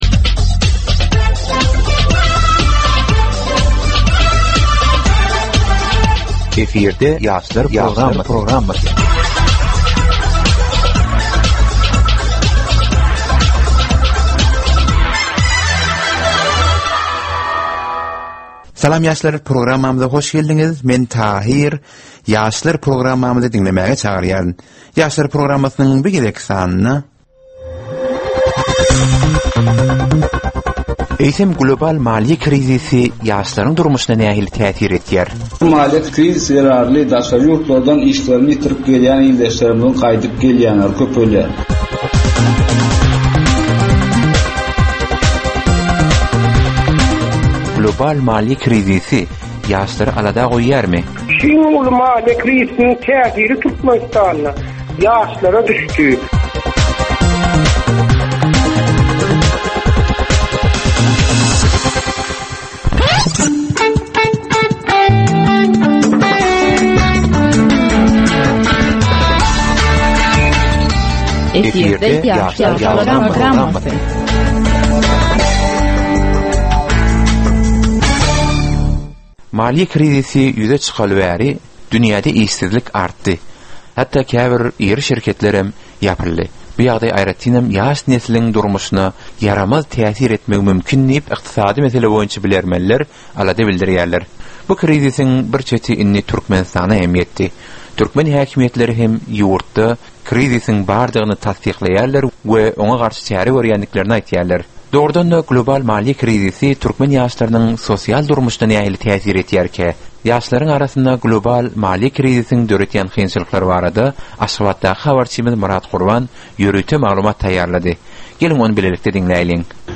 Türkmen we halkara yaslarynyn durmusyna degisli derwaýys meselelere we täzeliklere bagyslanylyp taýýarlanylýan 15 minutlyk ýörite geplesik. Bu geplesiklde ýaslaryn durmusyna degisli dürli täzelikler we derwaýys meseleler barada maglumatlar, synlar, bu meseleler boýunça adaty ýaslaryn, synçylaryn we bilermenlerin pikrileri, teklipleri we diskussiýalary berilýär. Geplesigin dowmynda aýdym-sazlar hem esitdirilýär.